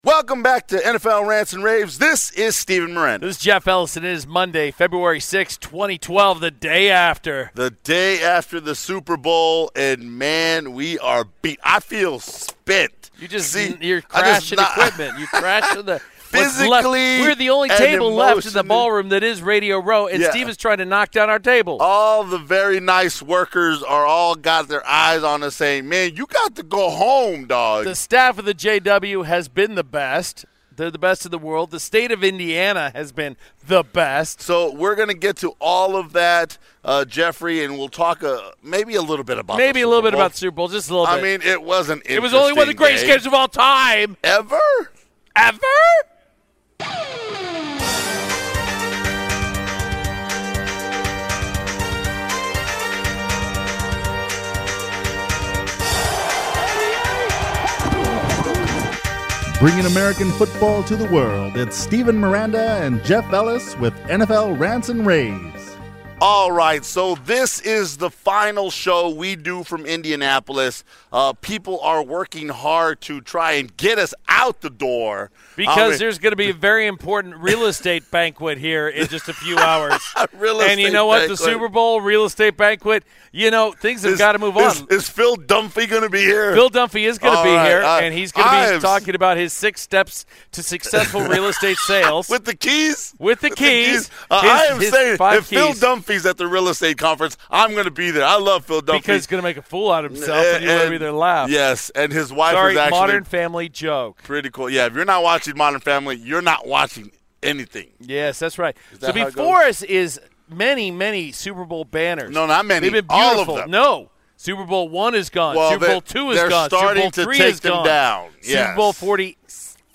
The guys are beat down and the last ones standing on Radio Row in Indianapolis. The great folks at the JW Marriott were nice enough to hook the power back up so the guys could record a final podcast from the Indy and talk about Super Bowl 46.